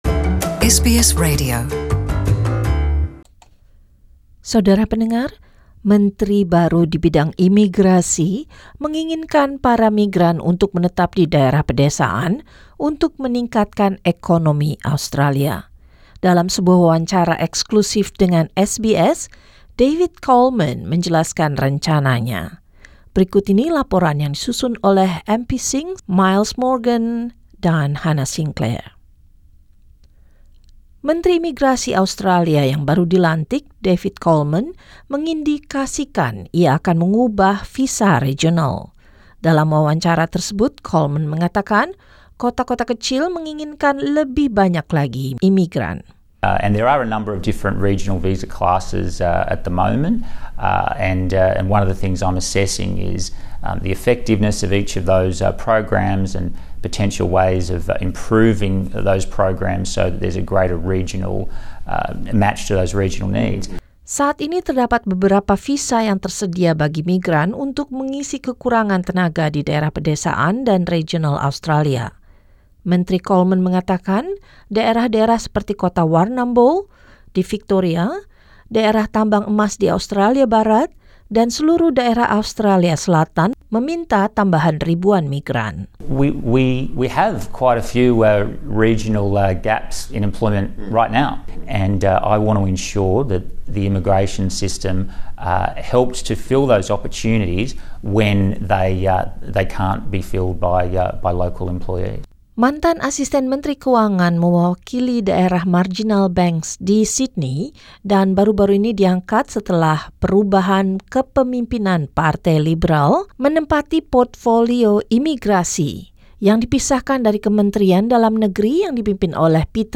In an exclusive interview with SBS, David Coleman sat down with SBS to talk about his plans for the role of Immigration Minister.